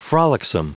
Prononciation du mot frolicsome en anglais (fichier audio)
Prononciation du mot : frolicsome